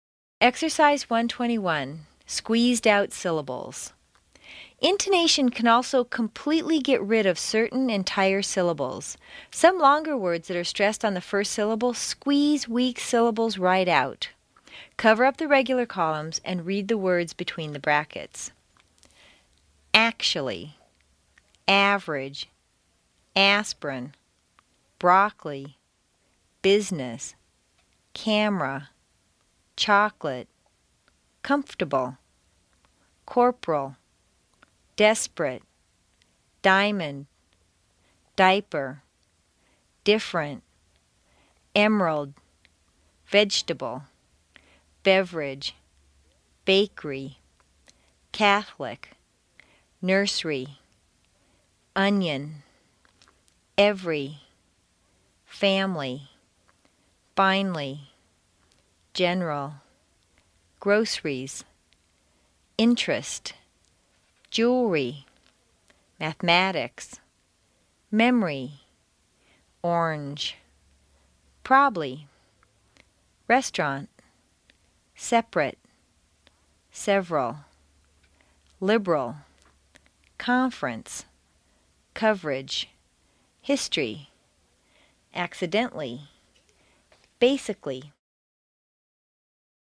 Note The ~cally ending is always pronounced ~klee.